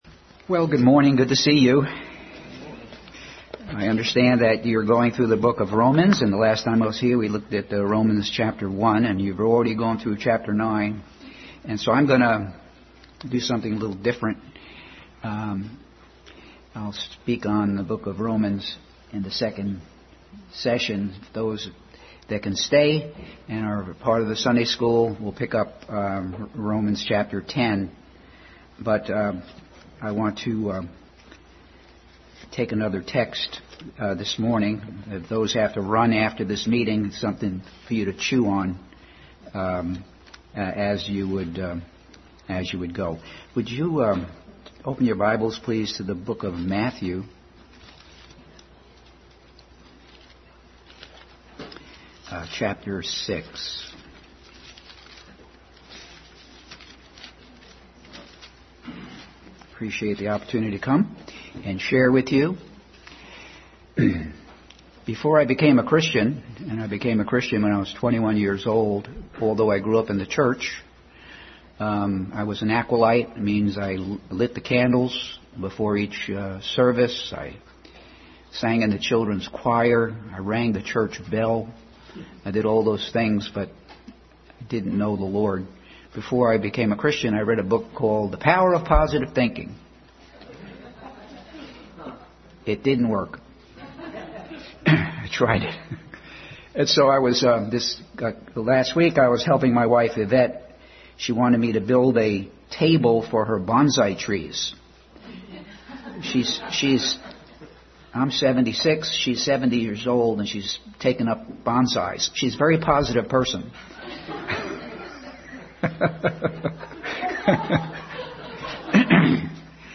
Morning message.
The Magic Kingdom vs The Kingdom of God Passage: Matthew 6:31-34, Exodus 7:10-11 Service Type: Sunday School Morning message.